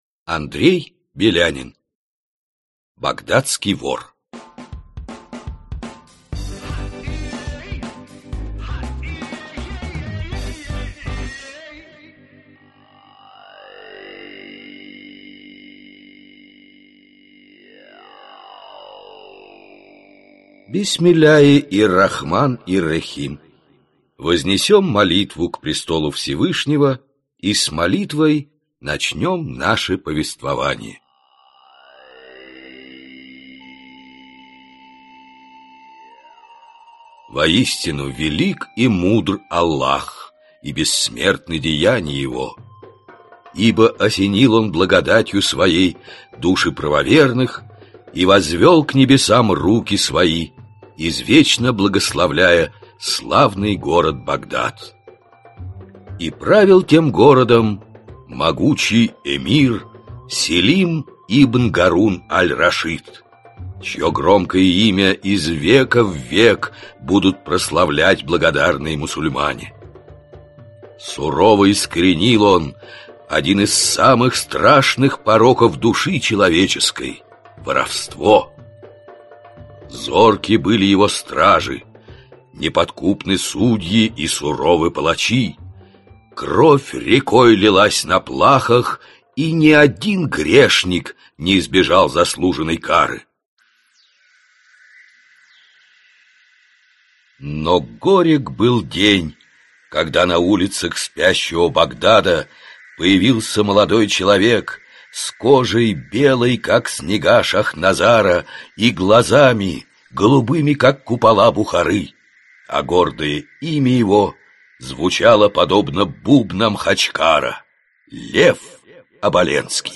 Аудиокнига Багдадский вор | Библиотека аудиокниг